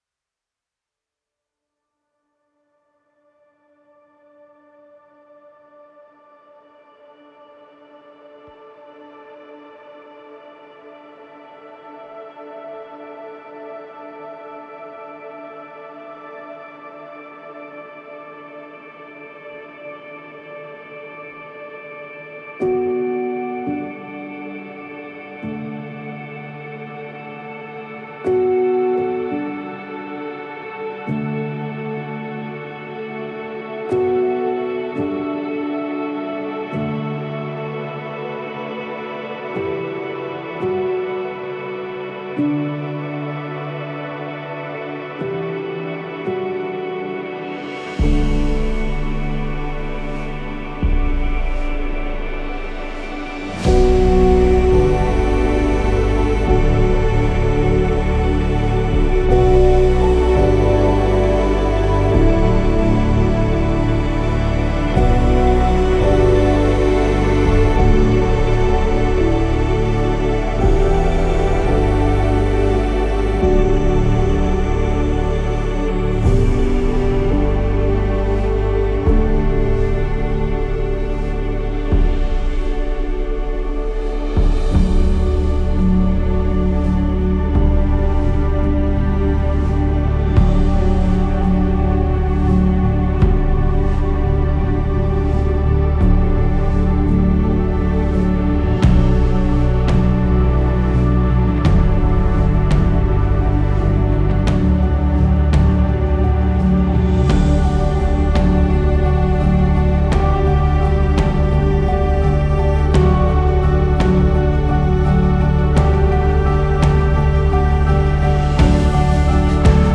Cinematic, post-rock music for tv/film
Post Rock